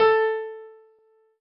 piano-ff-49.wav